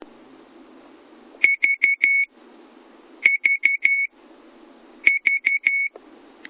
ブザー音（Futaba）
SUND_buzz_sw.M4A